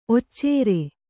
♪ occēre